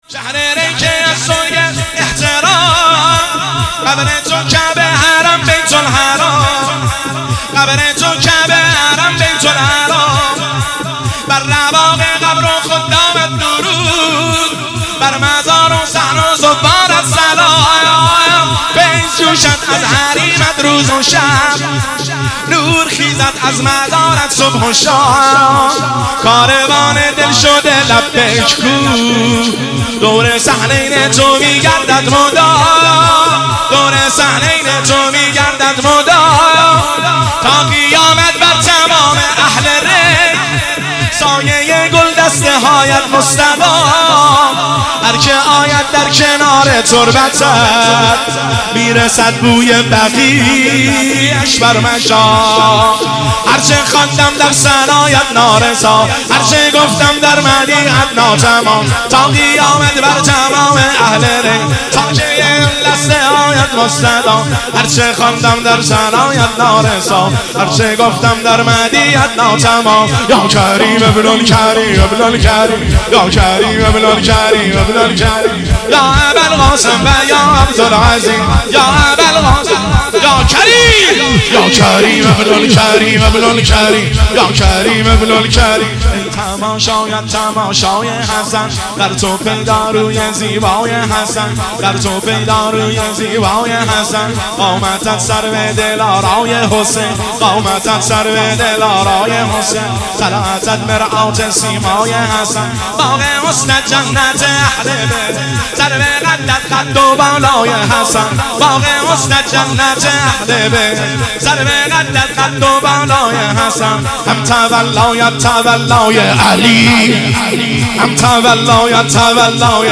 سرود – میلاد حضرت عبدالعظیم حسنی (ع) 1402